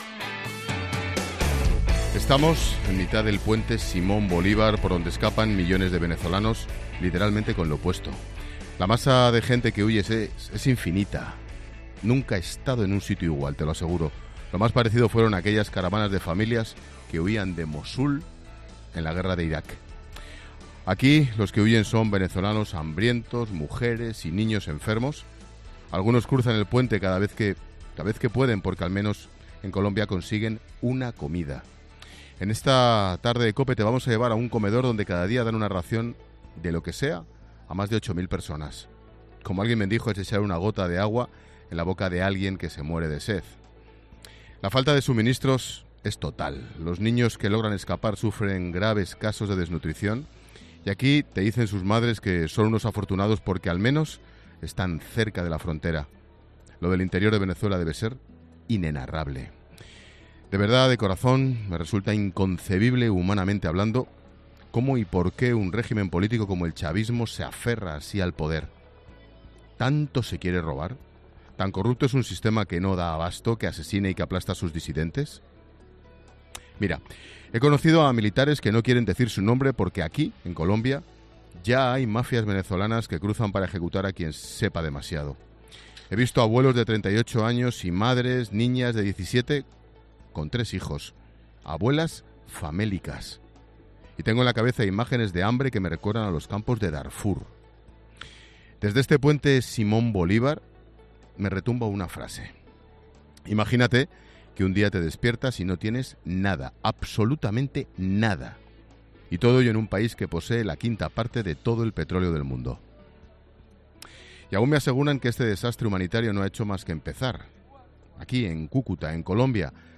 Monólogo de Expósito
Ángel Expósito analiza en su monólogo de las 16 horas la situación de Venezuela desde el puente Simón Bolivar, salida de Venezuela hacia Colombia.